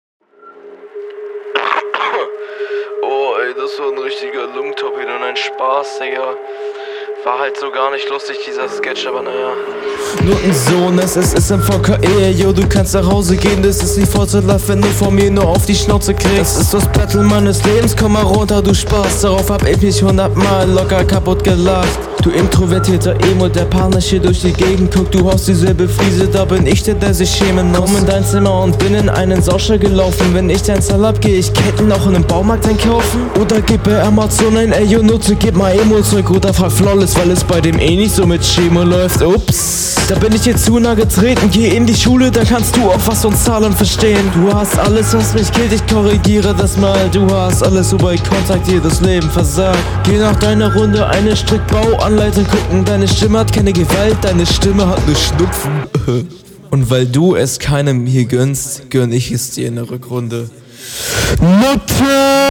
- Finde die distorted Doubles im Mix cool. Passt auch ganz nett zum Beat. - …